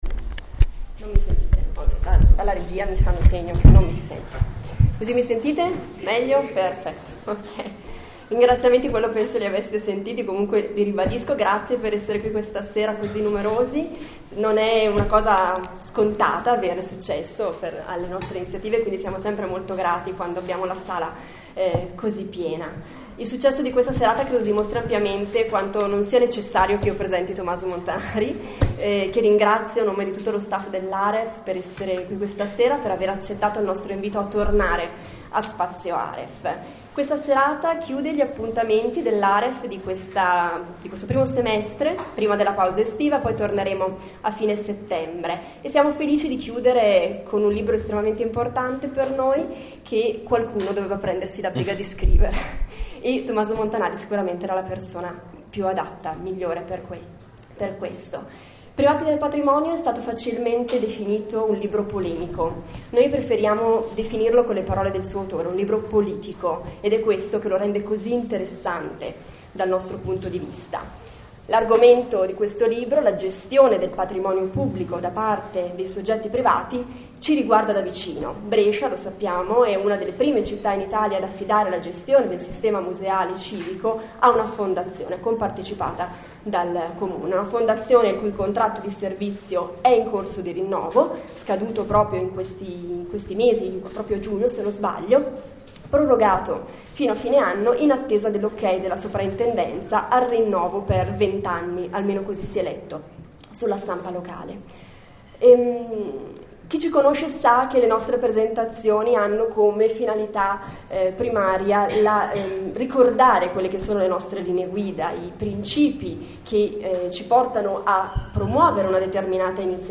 Ascolta la conferenza registrata e pubblicata su Facebook da Spazio Aref.